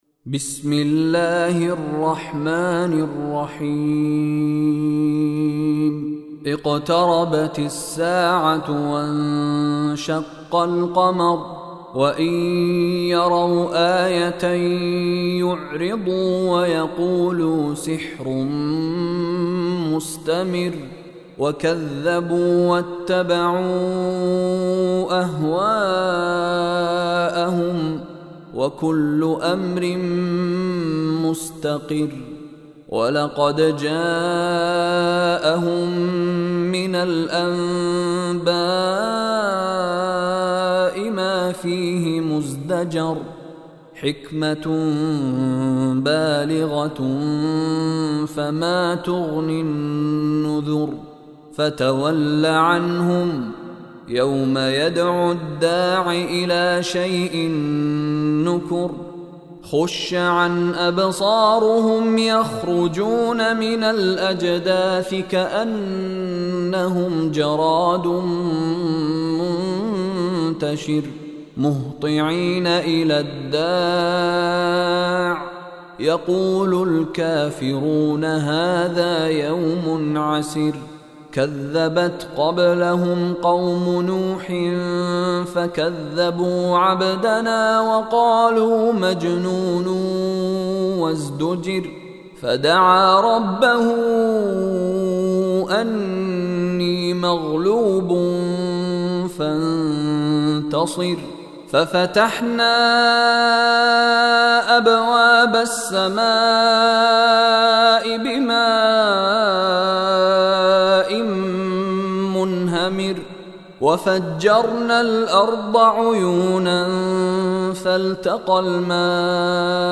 Surah Qamar MP3 Recitation by Mishary Rashid
Surah Al-Qamar is 54 chapter of Holy Quran. Listen online and download beautiful recitation / tilawat of Surah Qamar in the voice of Sheikh Mishary Rashid Alafasy.